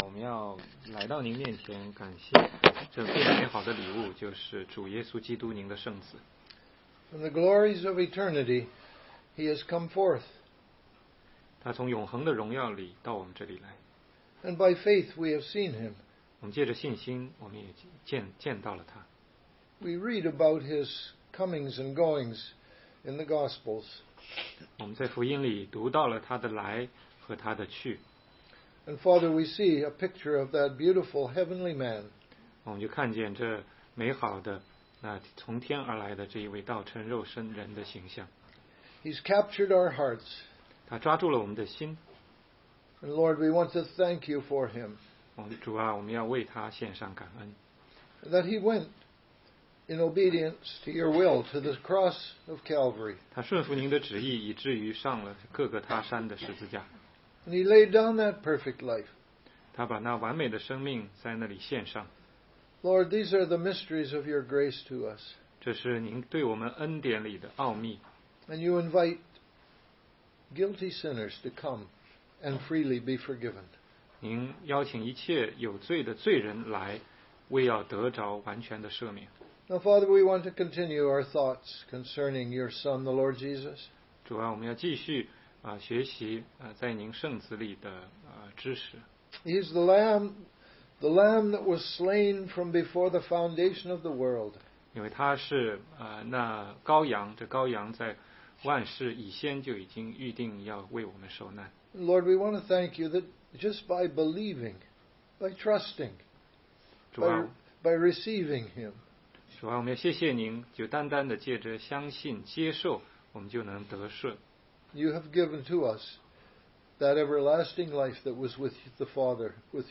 16街讲道录音 - 怎样才能读懂圣经系列之十二